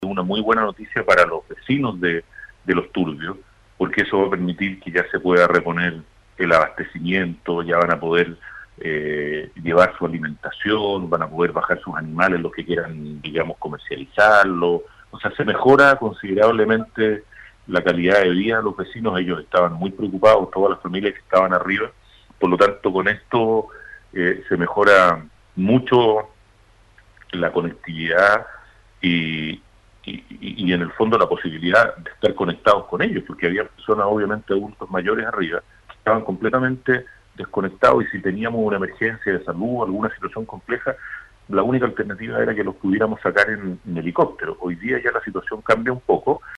Vialidad realizó la construcción de una vía de emergencia, por lo que solo pueden transitar vehículos 4 x 4, pero que sin duda es de gran ayuda para los vecinos ante cualquier emergencia, sostuvo el gobernador de Palena, José Luis Carrasco.